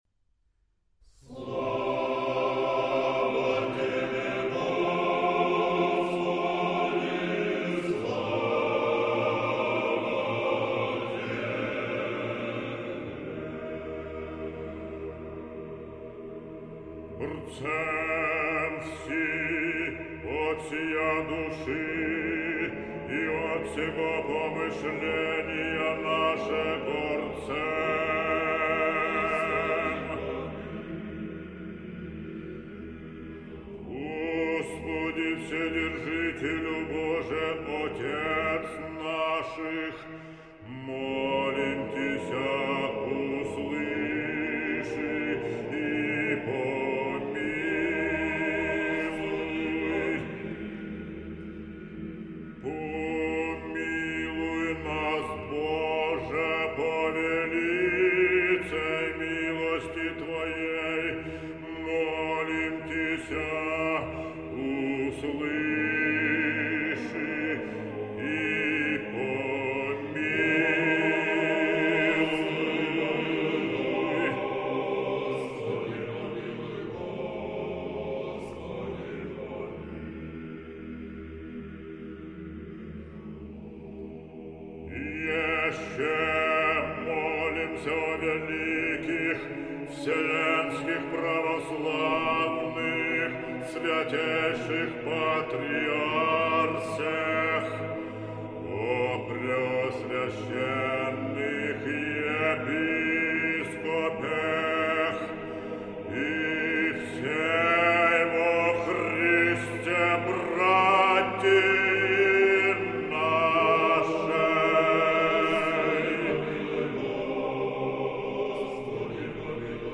А тут Профундо.То есть басы еще на октаву ниже поют.
Ортокс хор.Басы Профундо.